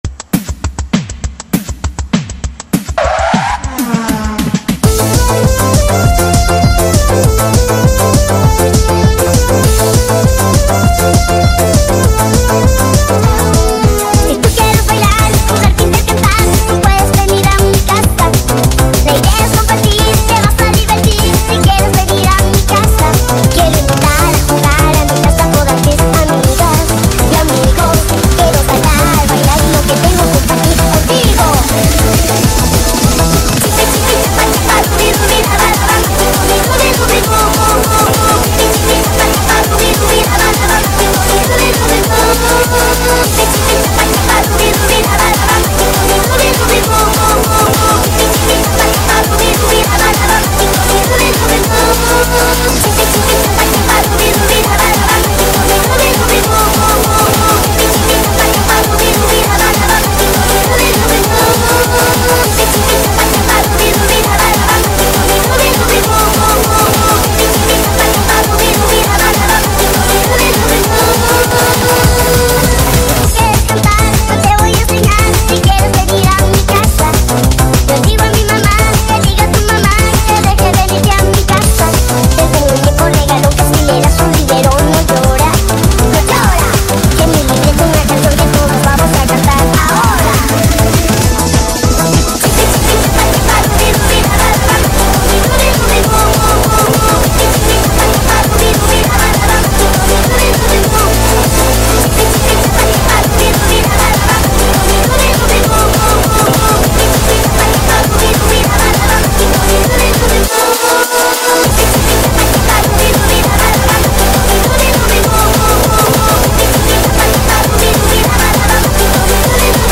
ремикс